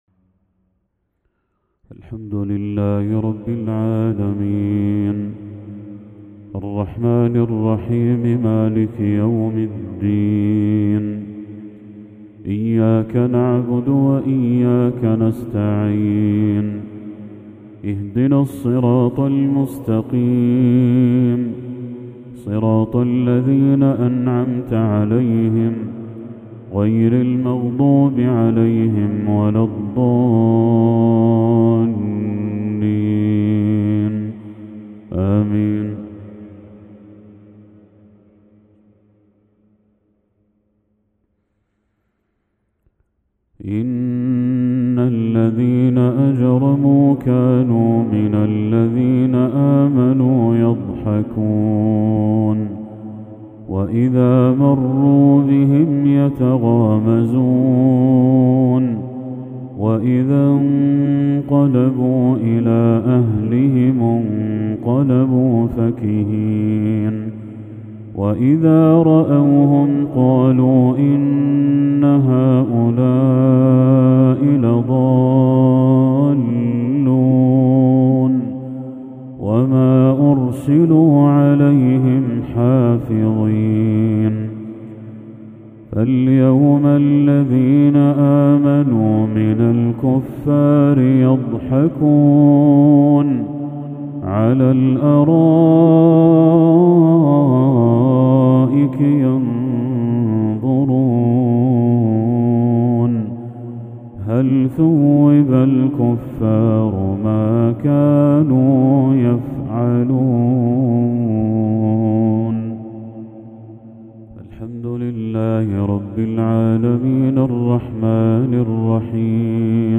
تلاوة من سورتي المطففين و الشرح للشيخ بدر التركي | مغرب 2 ذو الحجة 1445هـ > 1445هـ > تلاوات الشيخ بدر التركي > المزيد - تلاوات الحرمين